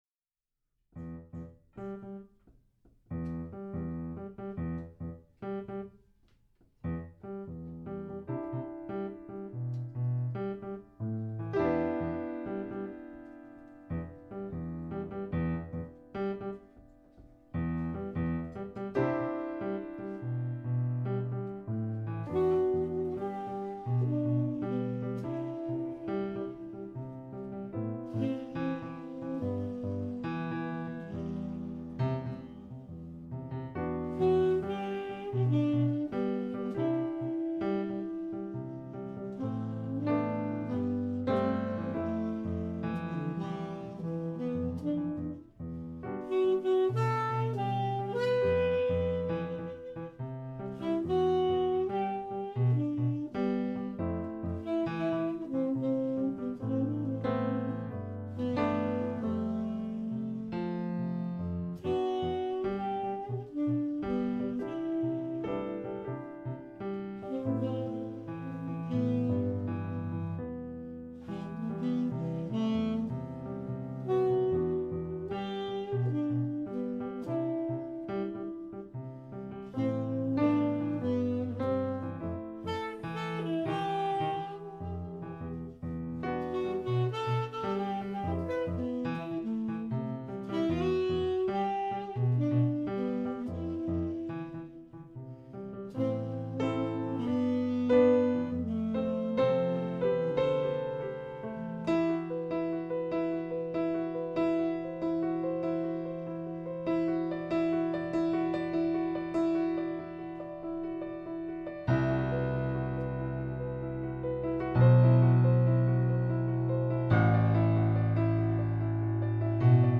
It´s about vibes, it´s about swing, it´s Jazz.